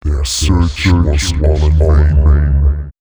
042 male.wav